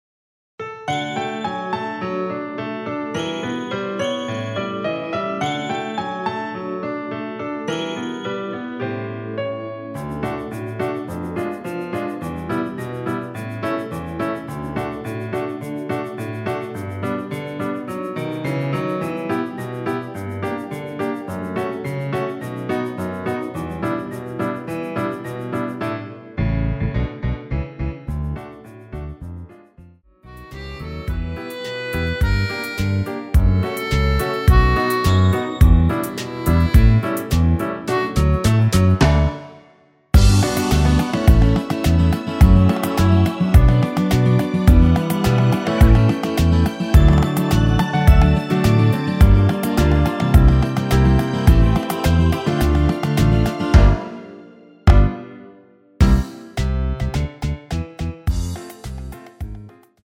앞부분30초, 뒷부분30초씩 편집해서 올려 드리고 있습니다.
위처럼 미리듣기를 만들어서 그렇습니다.